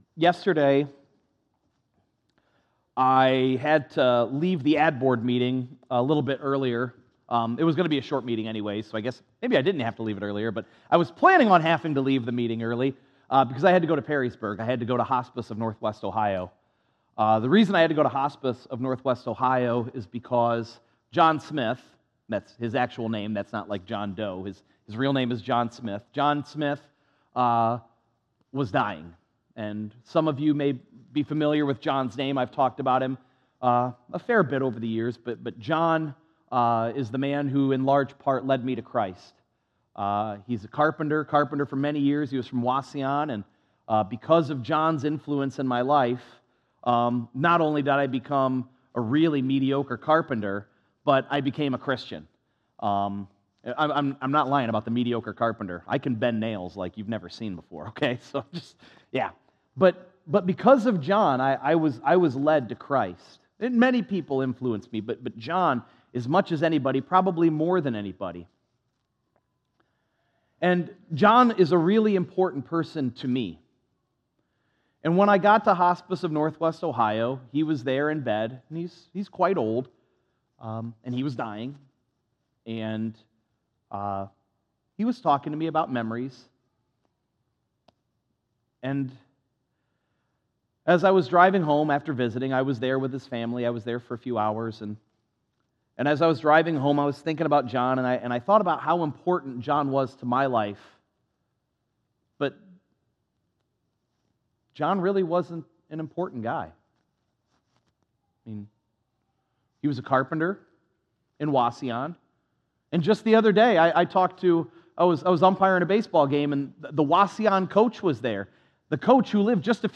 6_2_24_sunday_sermon.mp3